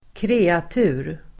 Uttal: [²kr'e:atu:r]